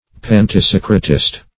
Search Result for " pantisocratist" : The Collaborative International Dictionary of English v.0.48: Pantisocratist \Pan`ti*soc"ra*tist\, n. One who favors or supports the theory of a pantisocracy.